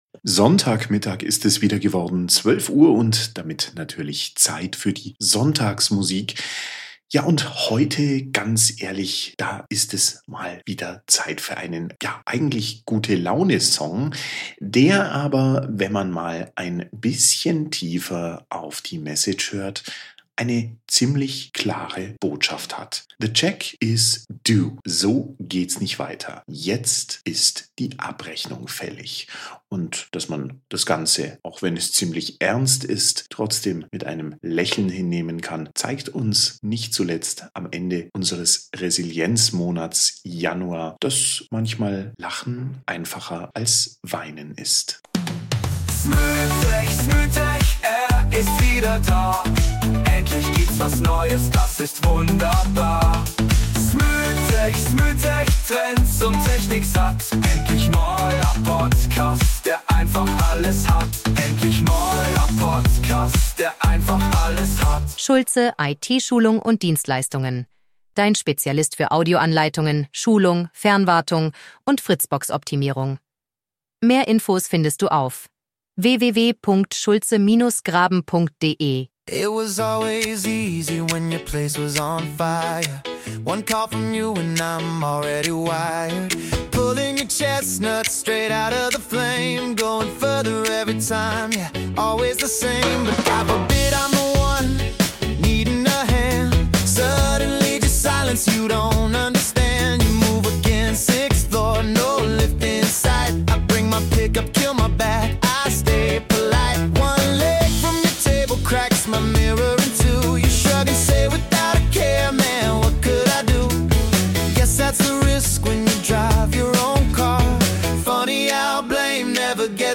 ersten Blick nach Gute-Laune-Nummer klingt, aber
bringt das mit einem leichten Sound rüber, aber die Message ist